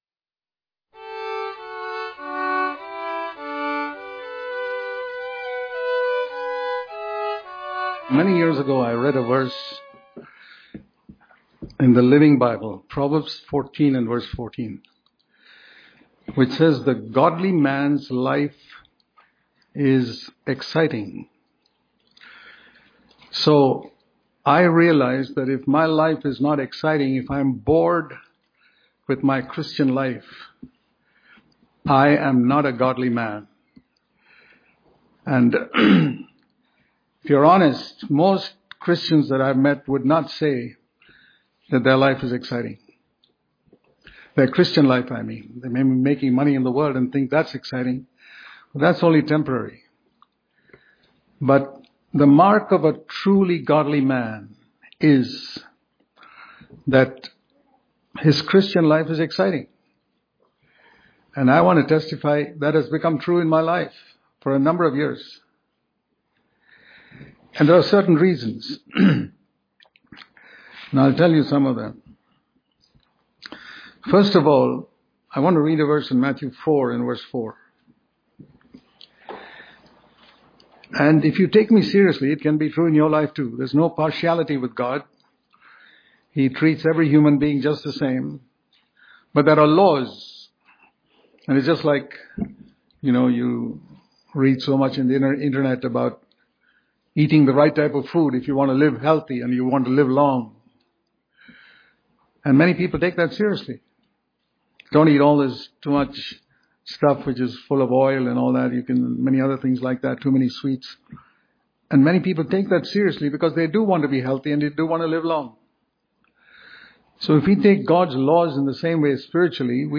Daily Devotion